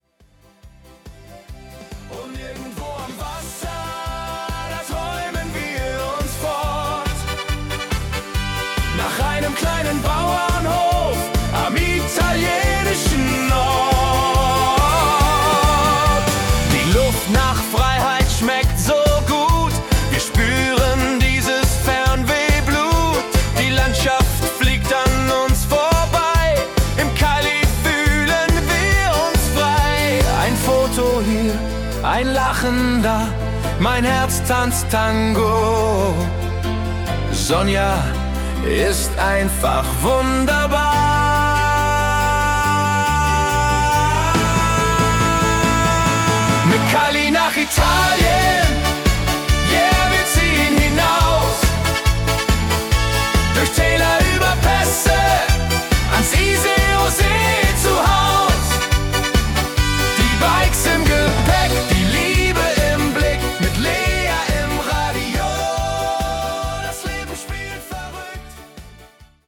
Volkstümlich